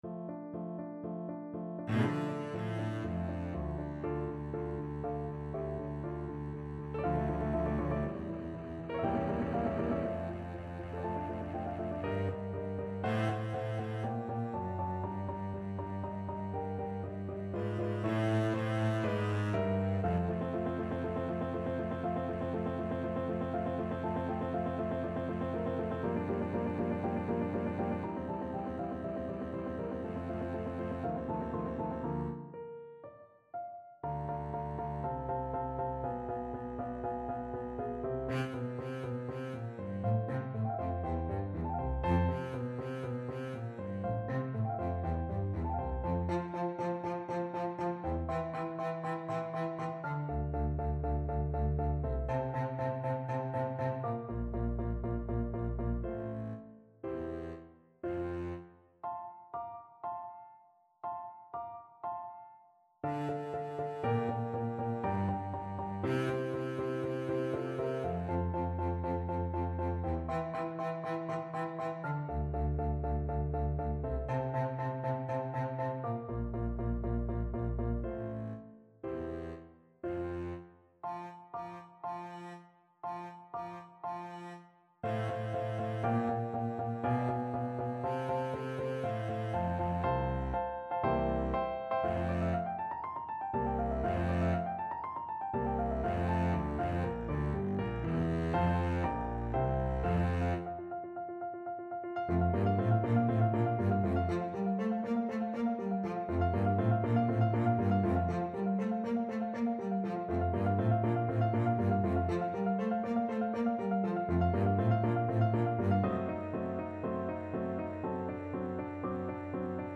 Cello-Bass Duet version
CelloDouble Bass
4/4 (View more 4/4 Music)
Classical (View more Classical Cello-Bass Duet Music)